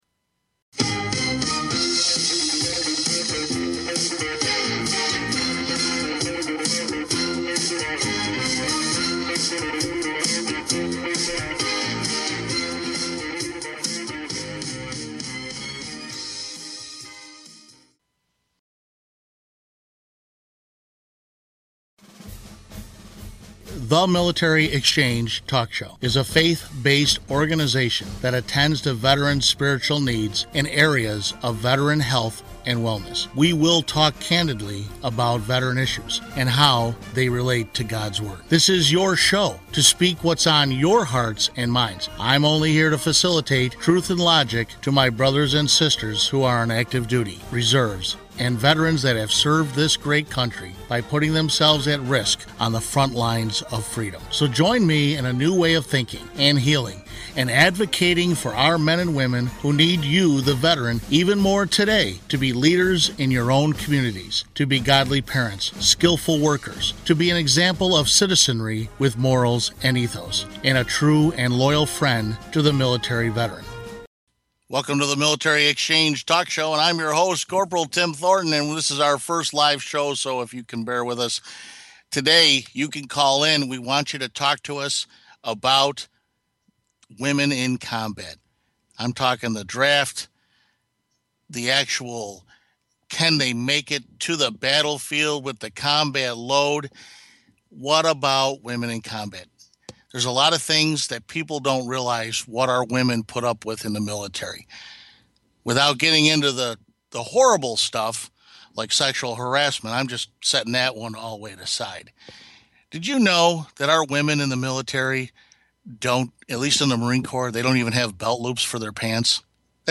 The Military Exchange Talk Show